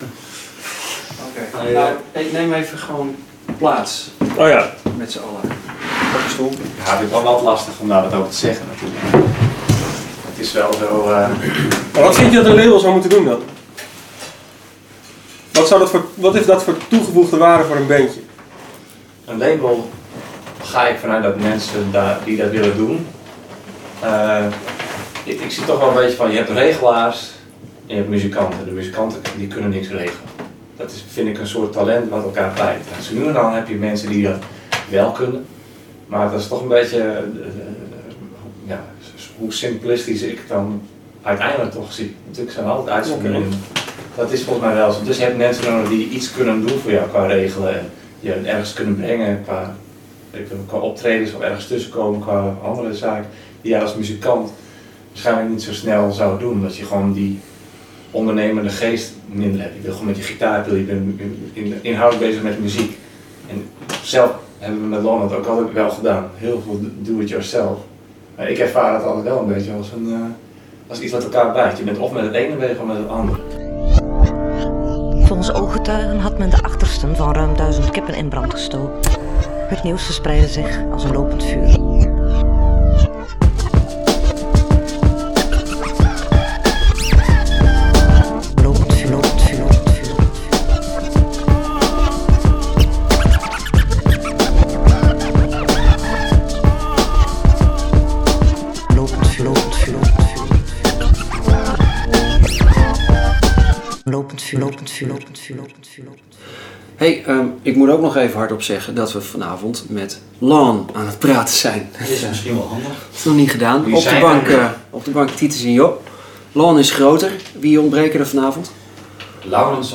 In podcast 34 zijn we vereerd door een bezoek van Lawn. Ze spelen niet alleen 3 fijne nummers maar vertellen ook over hun ‘Safe Haven’ concept, hun plannen voor de toekomst en geven ze ons een inkijkje hoe hun laatste album tot stand kwam.